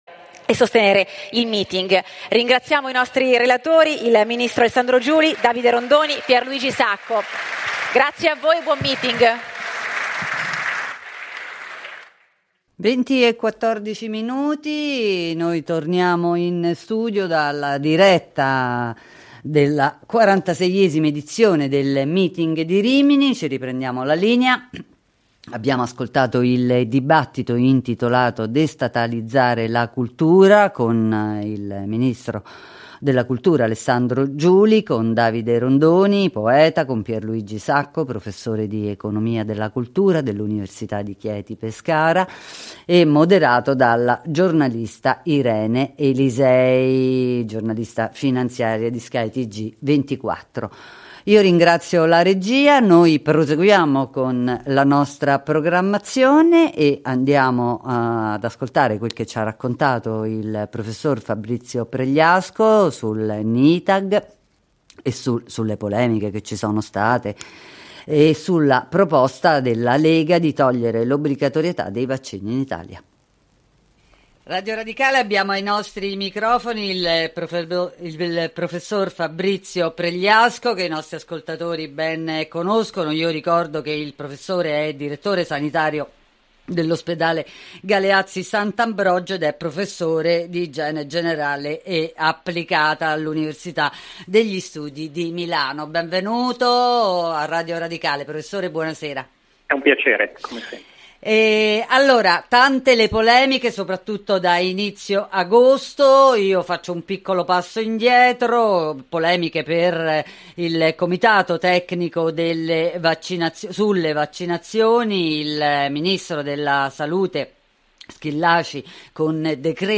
Dell’illuminante conversazione mettiamo in risalto alcuni punti.